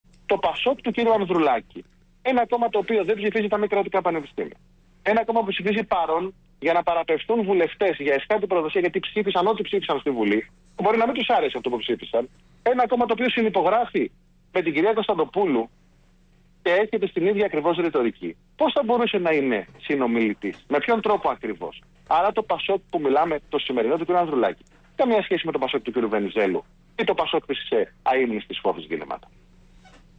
Για να μην υπάρχει καμία αμφιβολία, ιδού και το ηχητικό απόσπασμα  της συνέντευξής μου.